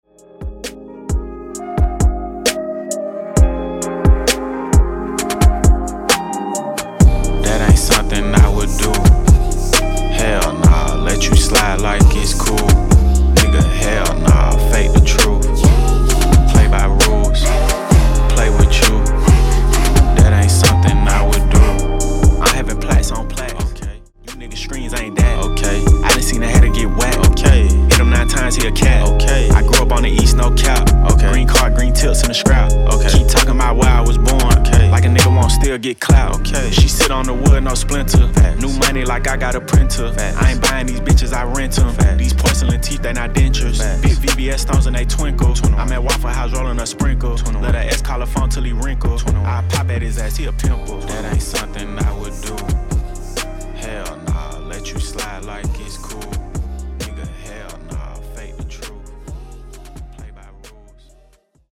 s214 Genres: HIPHOP , RE-DRUM Version: Dirty BPM: 66 Time